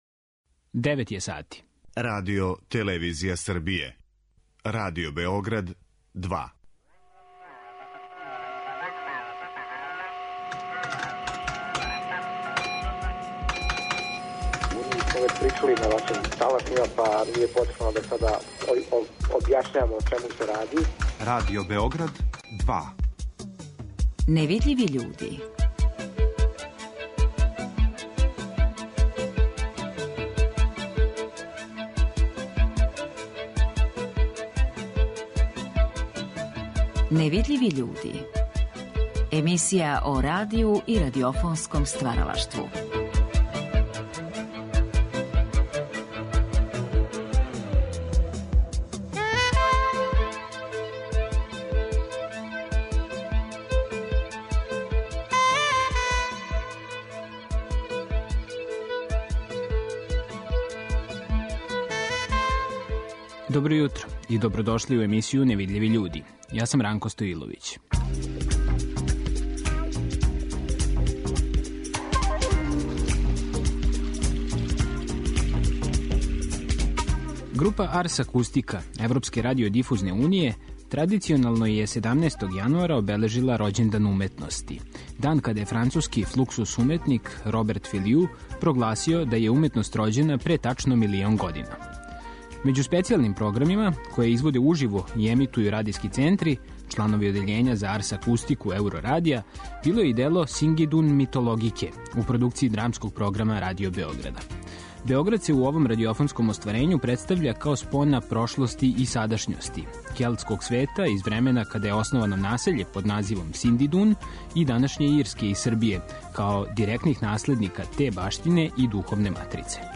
Ови разговори вођени су за циклус емисија "Гост Другог програма".
Емисија о радију и радиофонском стваралаштву.